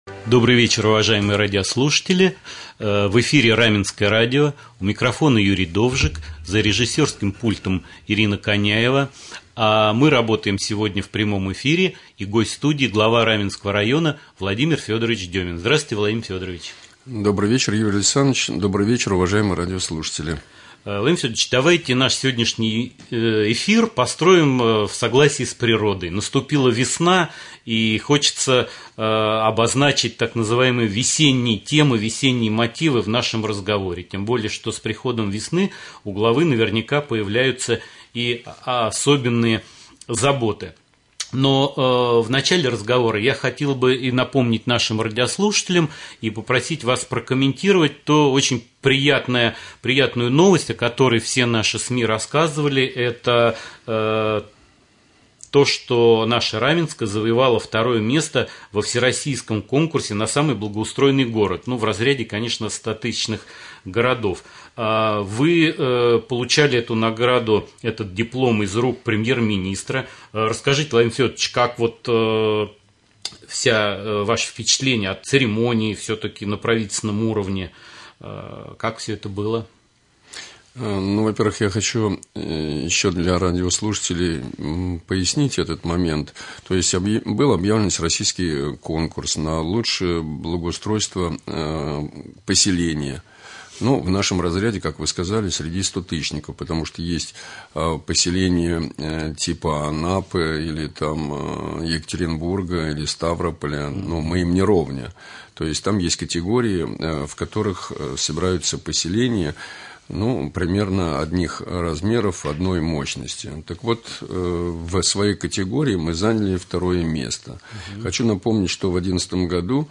Прямой эфир с главой Раменского муниципального района В.Ф.Деминым.
Прямой эфир с главой района